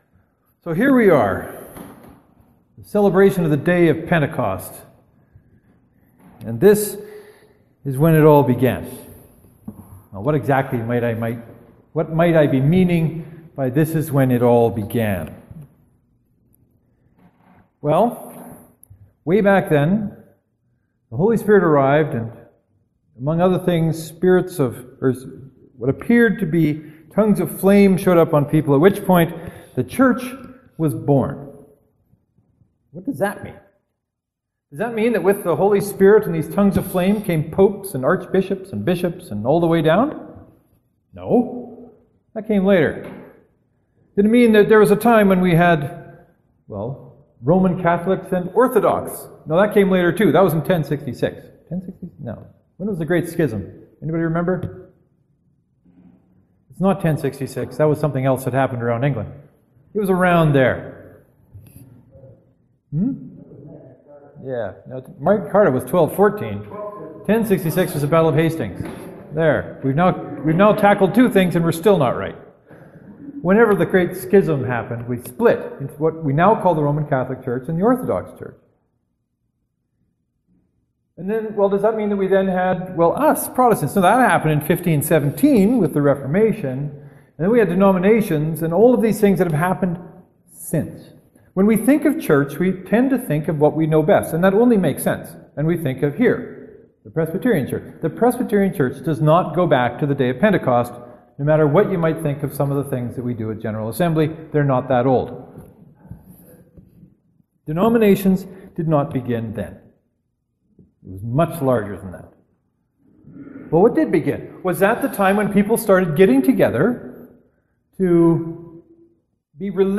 Knox Presbyterian This is when it all began (to download, right click and select “Save Link As .